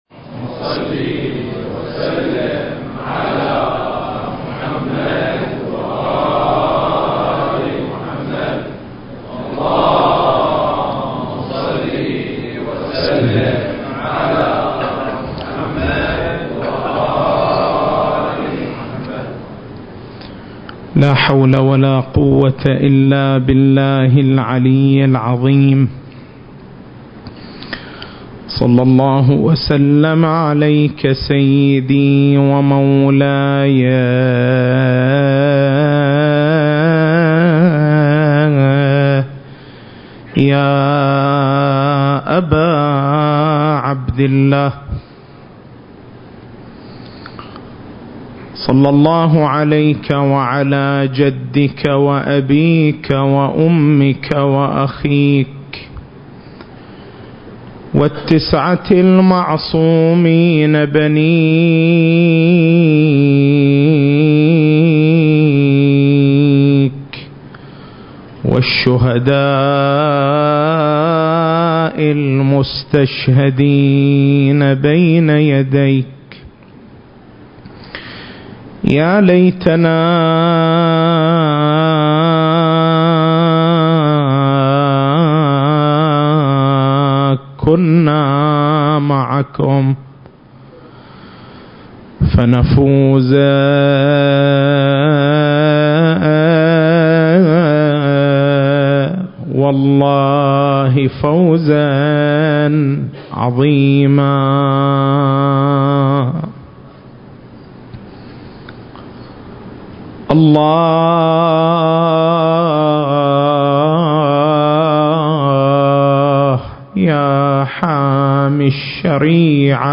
المكان: حسينية الحاج حبيب الحمران - القطيف